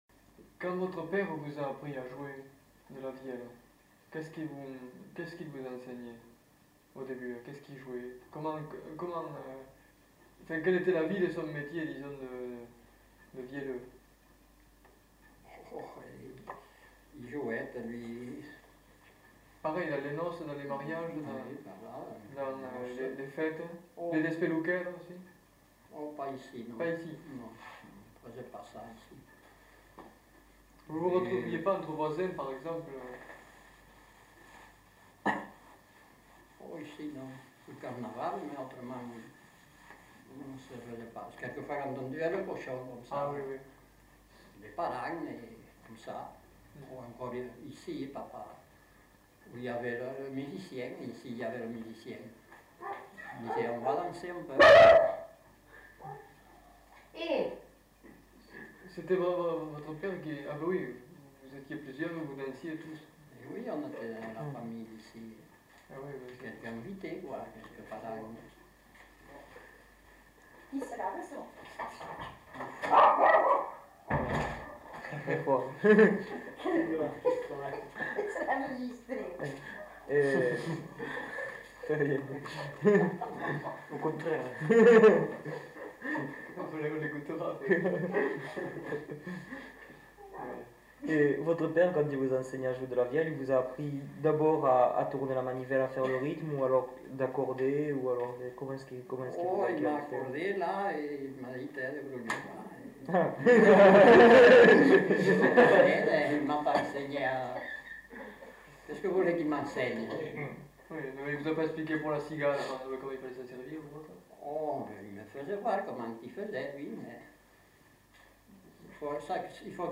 Aire culturelle : Petites-Landes
Lieu : Lencouacq
Genre : témoignage thématique